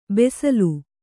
♪ besalu